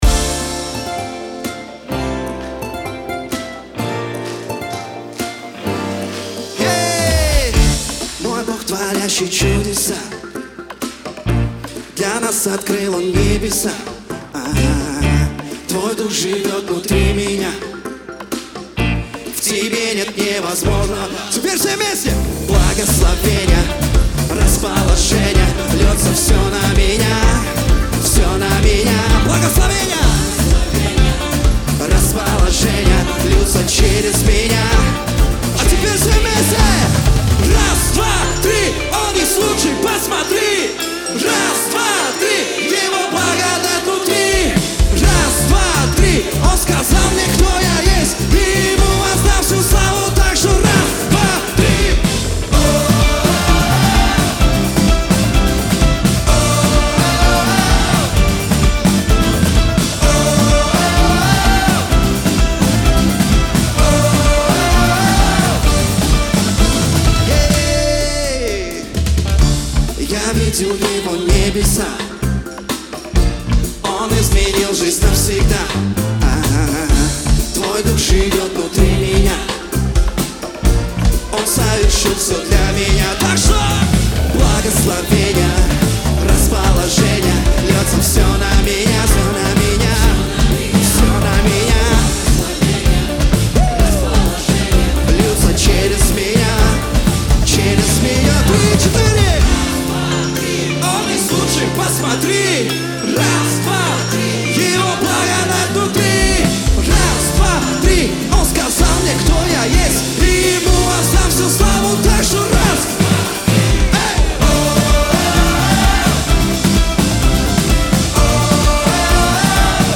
909 просмотров 1133 прослушивания 43 скачивания BPM: 128